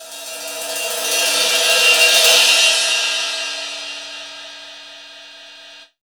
• Drum Crash E Key 07.wav
Royality free crash cymbal single hit tuned to the E note. Loudest frequency: 4217Hz
drum-crash-e-key-07-Jmg.wav